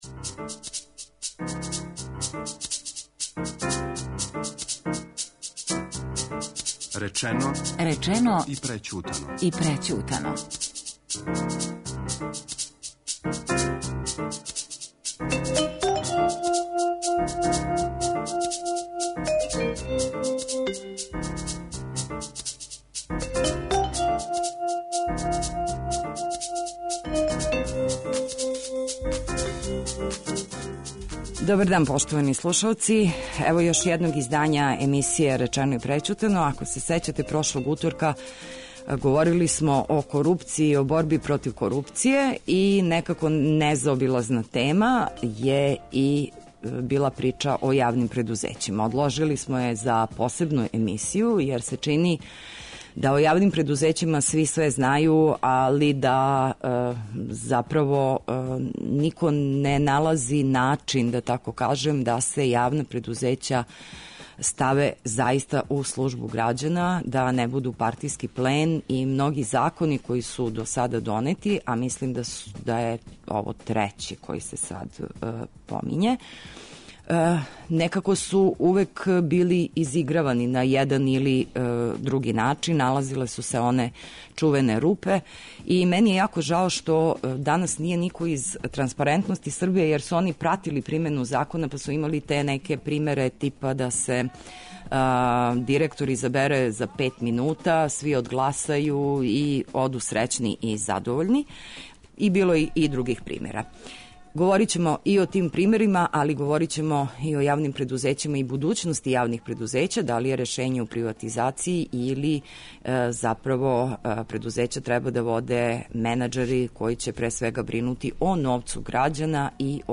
економски новинар
социолог